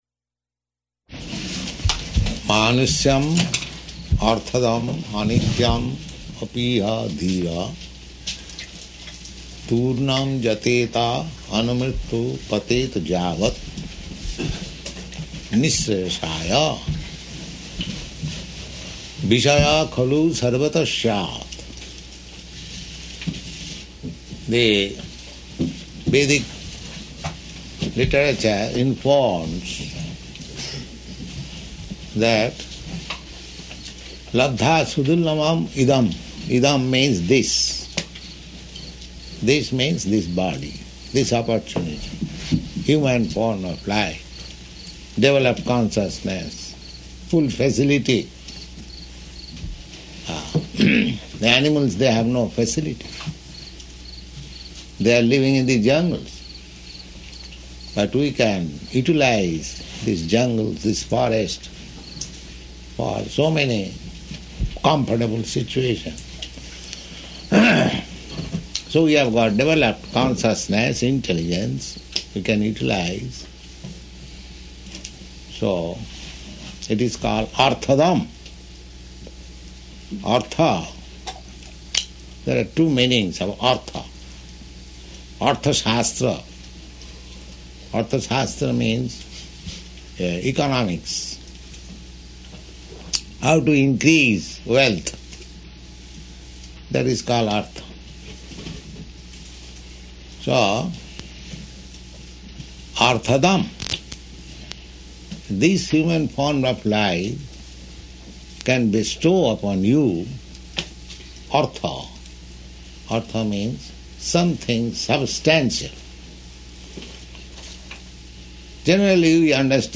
Initiation lecture [partially recorded]
Location: New Vrindavan